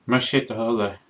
mashadahollay” (más é do thoil é = please) and “